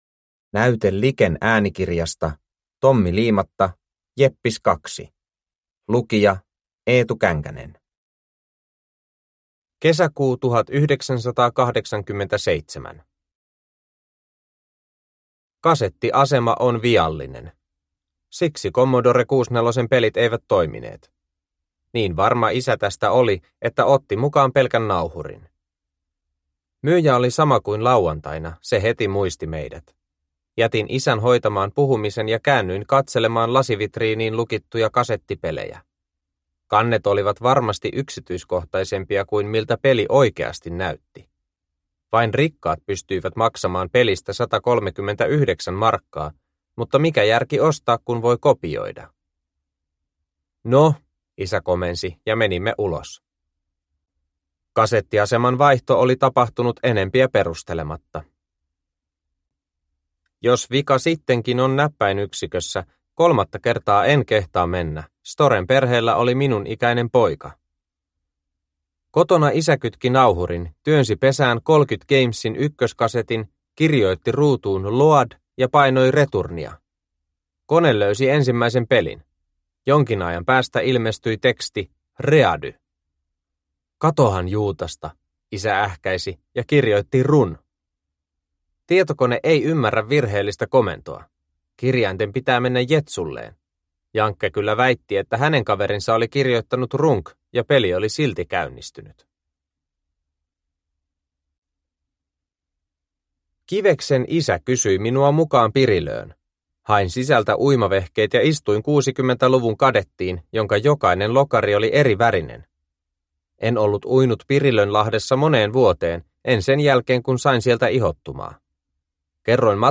Jeppis 2 – Ljudbok – Laddas ner